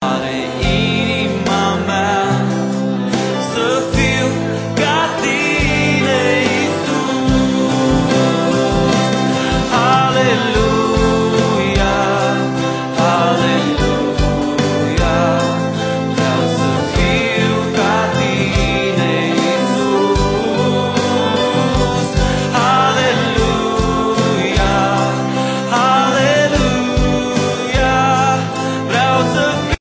Album de lauda si inchinare inregistrat live